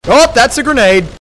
bounce